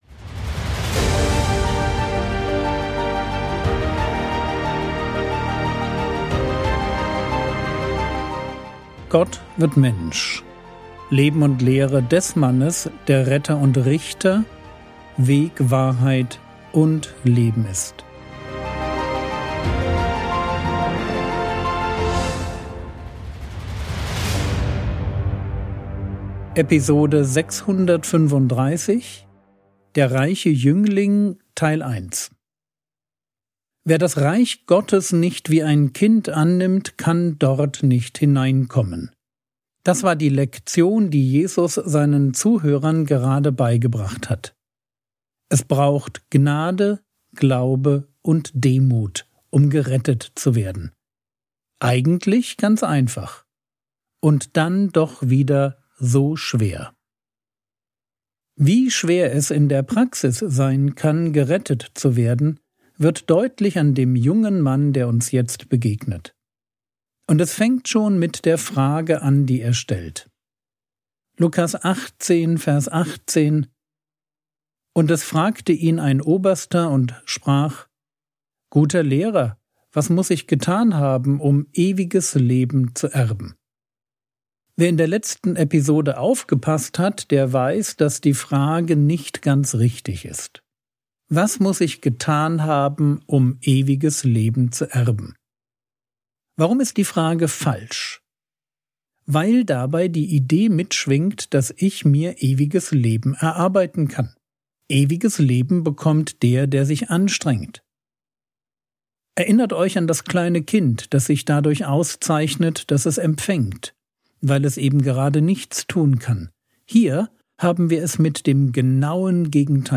Episode 635 | Jesu Leben und Lehre ~ Frogwords Mini-Predigt Podcast